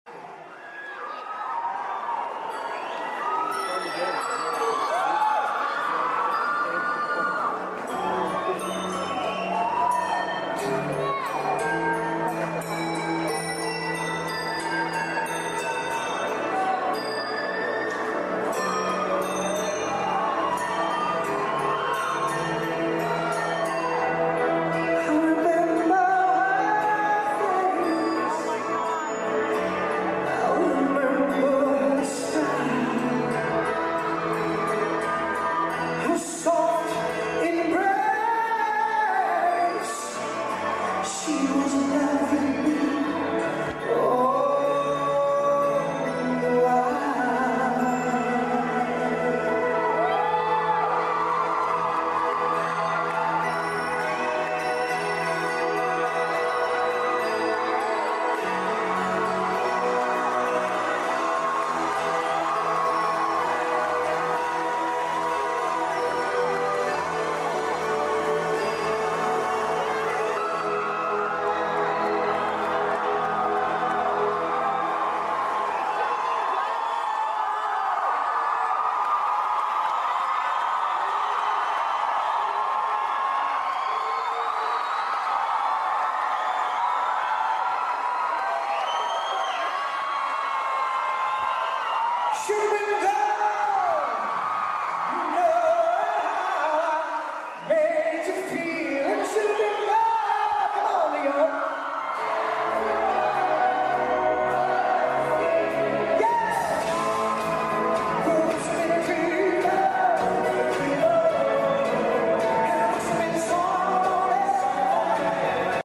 Live in NY 1994